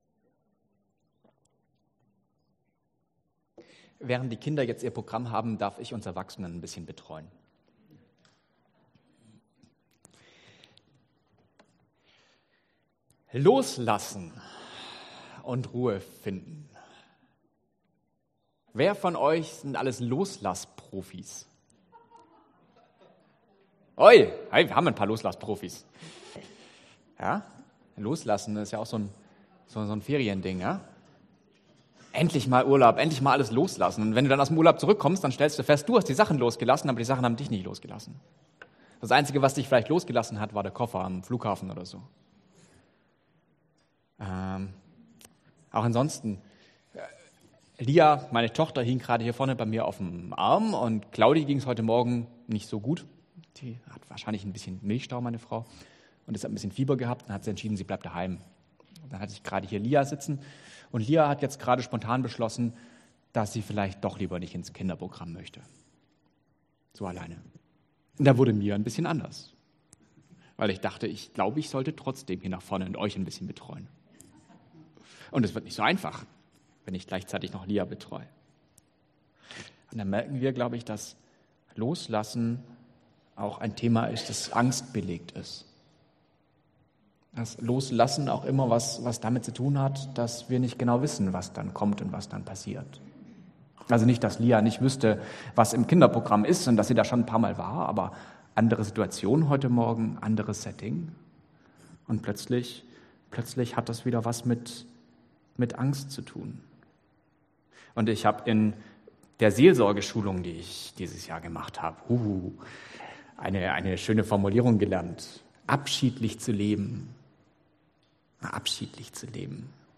Passage: Mt 11, 25-30 Dienstart: Gottesdienst Themen